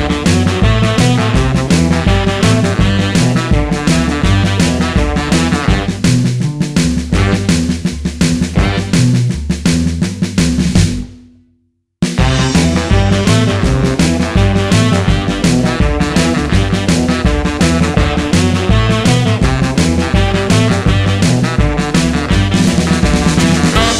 Two Semitones Down Rock 'n' Roll 2:14 Buy £1.50